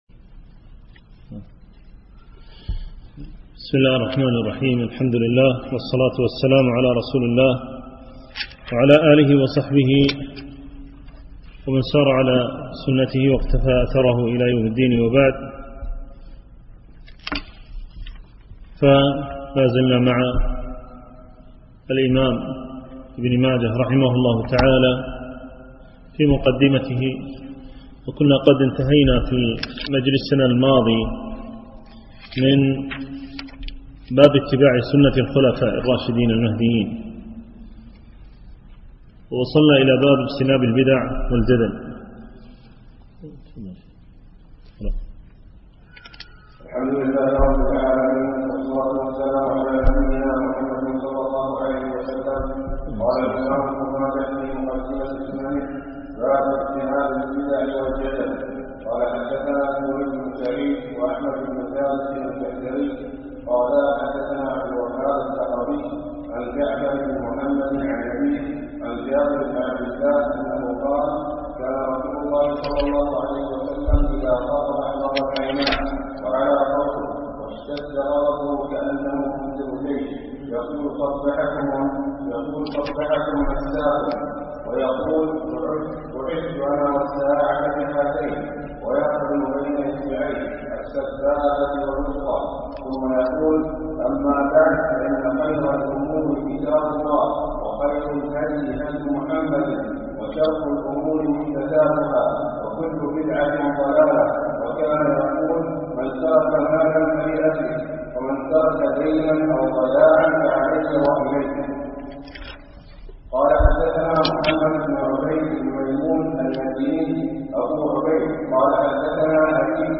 شرح أبوب من مقدمة سنن ابن ماجه - الدرس التاسع
دروس مسجد عائشة